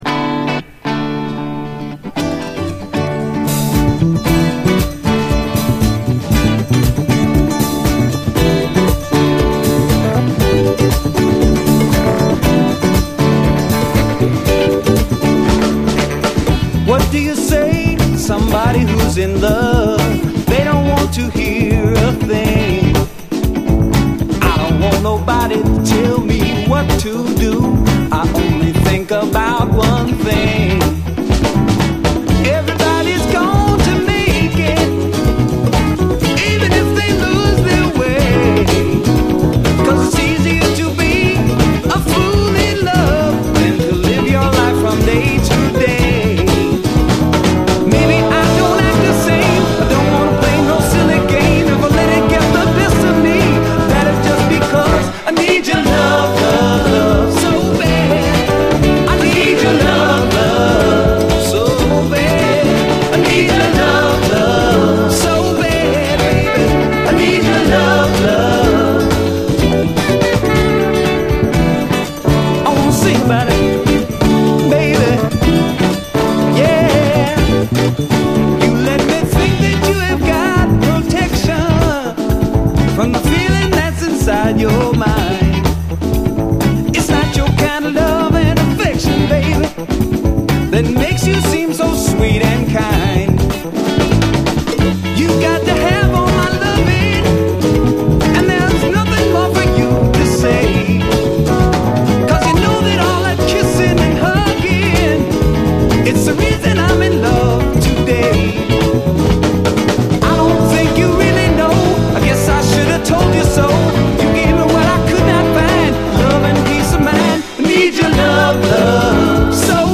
両面最高、めちゃくちゃカッコいいです。どちらも後半はインストへと接続。